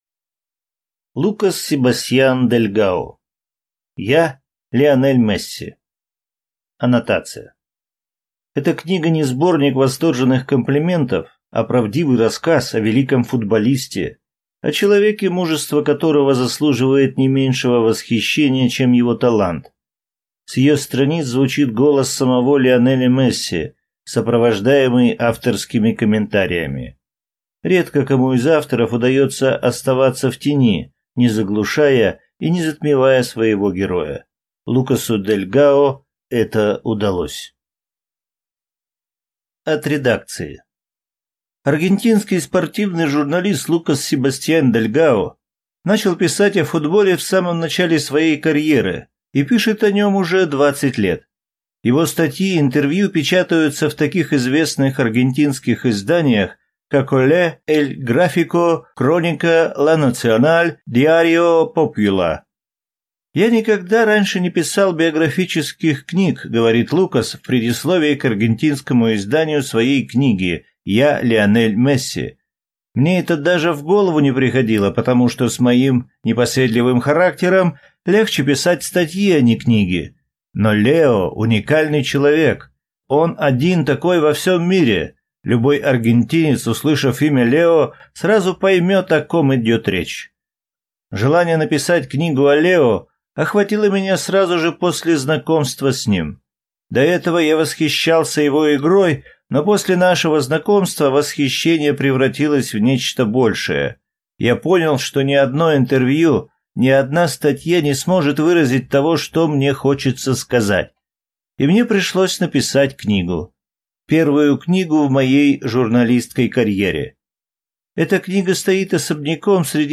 Аудиокнига Я, Лионель Месси | Библиотека аудиокниг